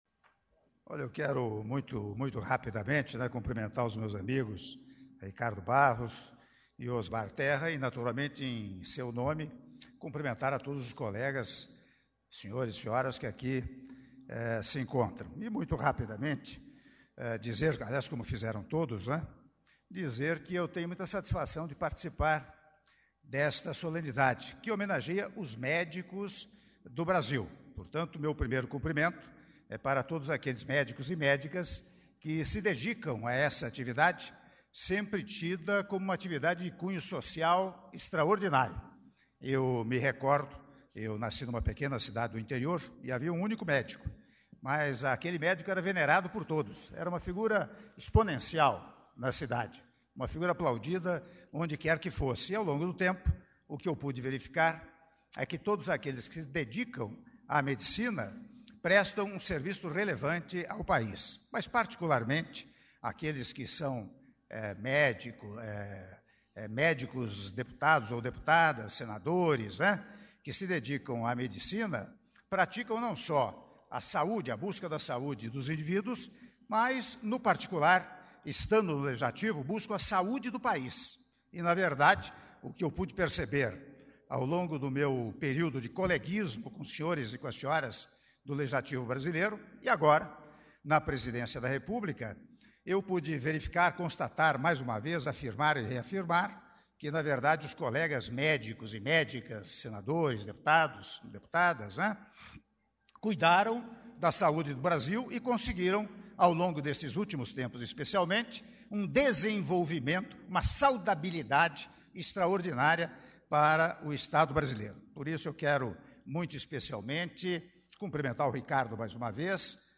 Áudio do discurso do Presidente da República, Michel Temer, durante Cerimônia de Entrega de Insígnias da Ordem do Mérito Médico - (02min48s) - Brasília/DF